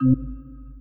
some more puzzle sounds
press.wav